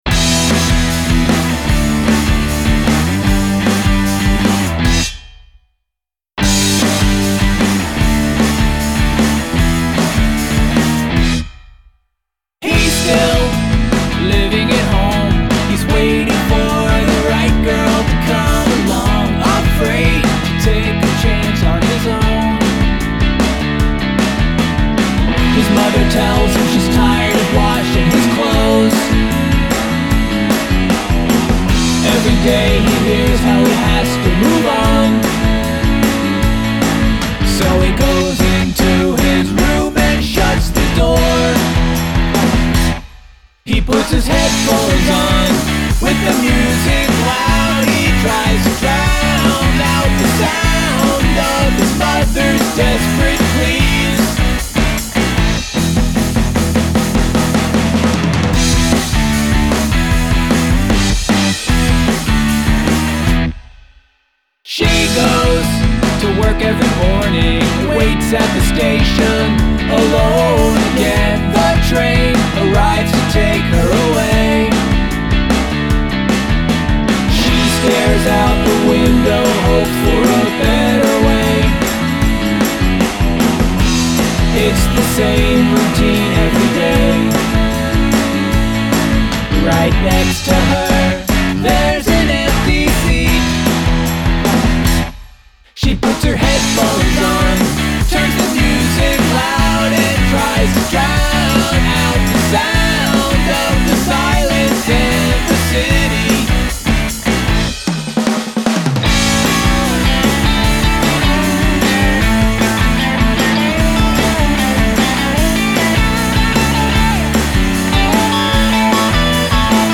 A judicious use of silence